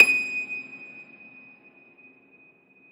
53e-pno23-D5.wav